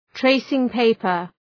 Προφορά
{‘treısıŋ,peıpər}